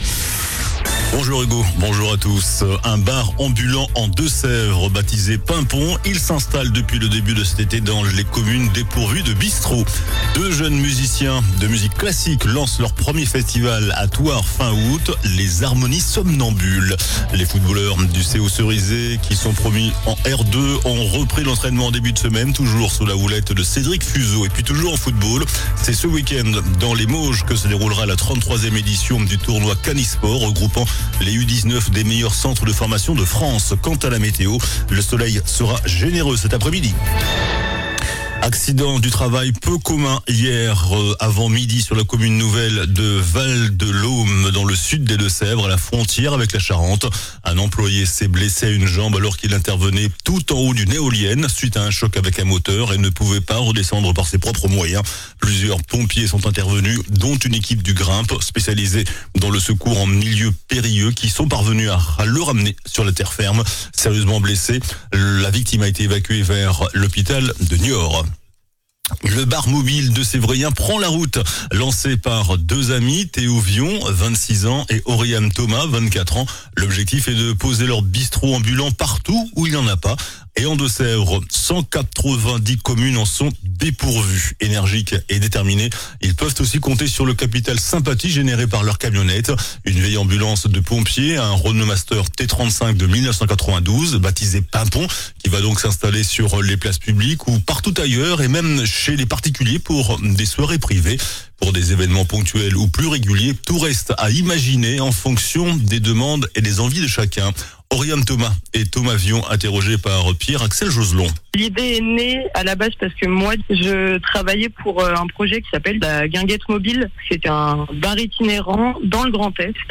JOURNAL DU JEUDI 31 JUILLET ( MIDI )